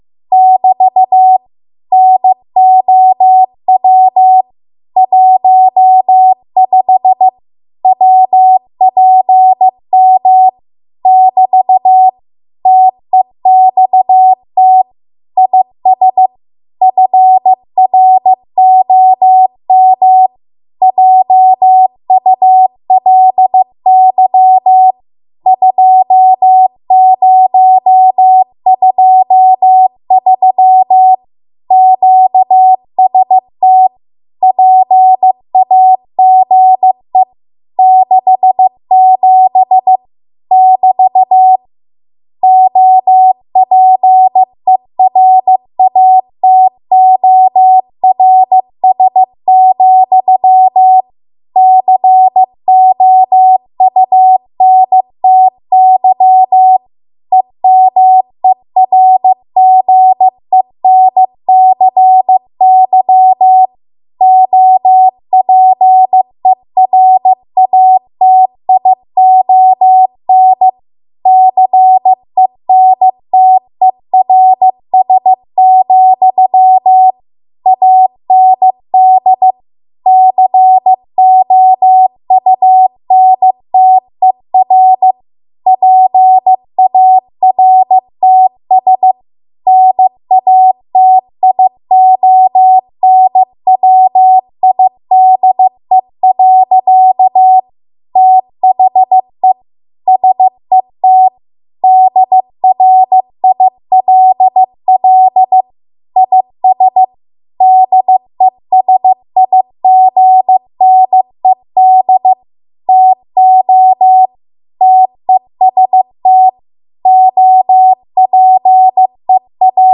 15 WPM Code Practice Archive Files
Listed here are archived 15 WPM W1AW code practice transmissions for the dates and speeds indicated.
You will hear these characters as regular Morse code prosigns or abbreviations.